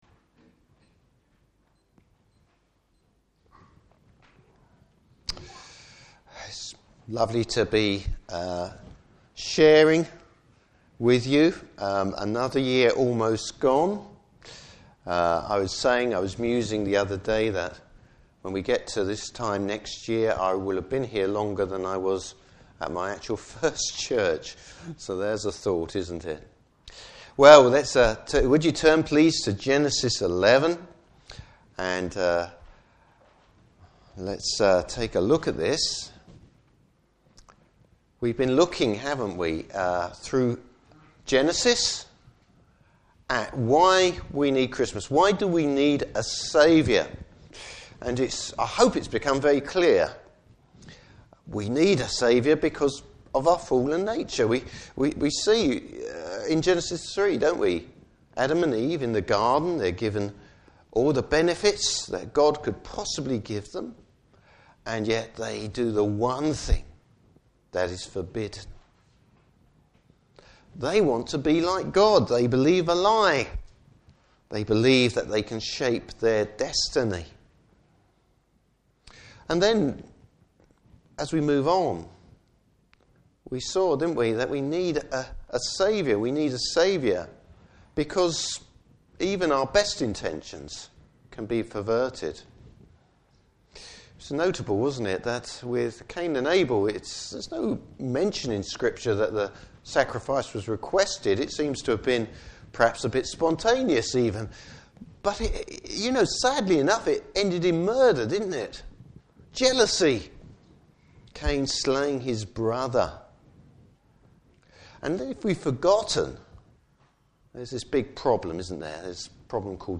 Service Type: Morning Service Bible Text: Genesis 11:1-9.